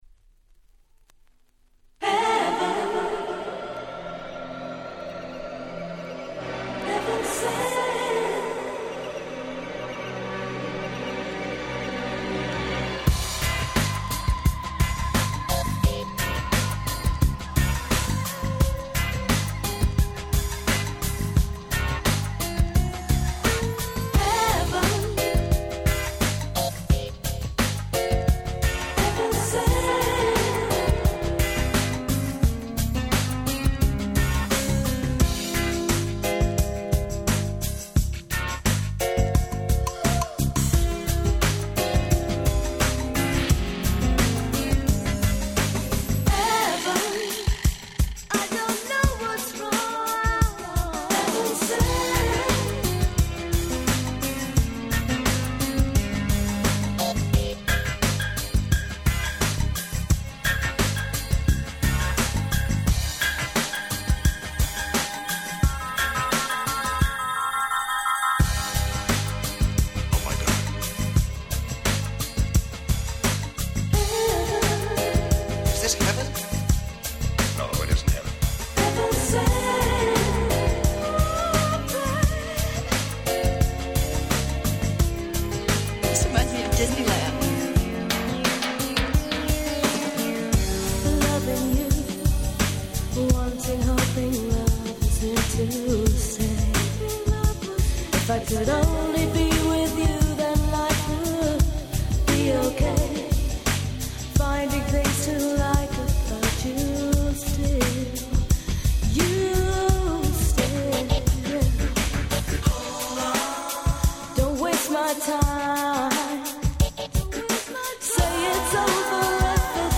) UK Original Press. 93' Nice UK R&B !!
爽やかで切ないMidチューンで彼女のボーカルが本当に心地良いです。